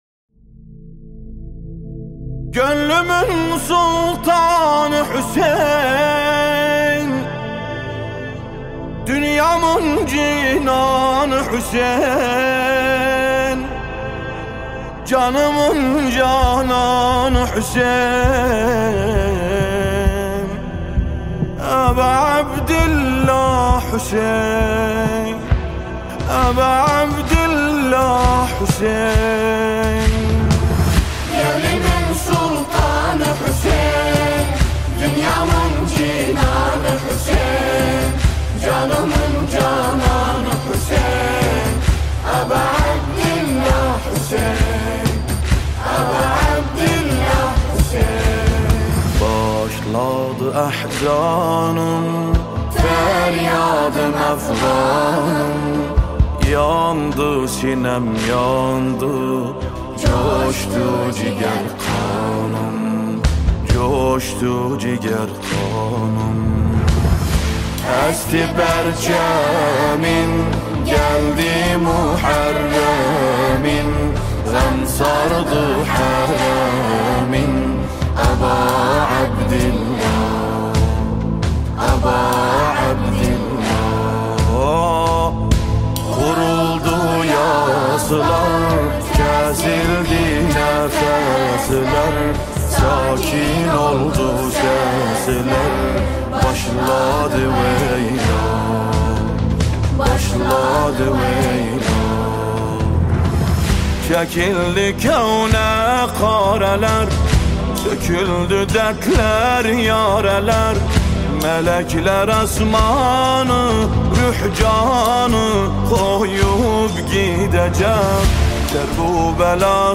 نوحه ترکی عراقی
با صدای دلنشین